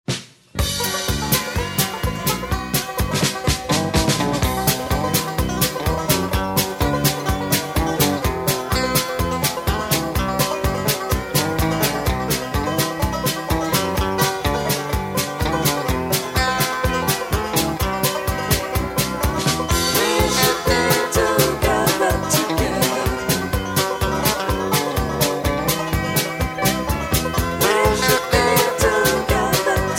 Singing Call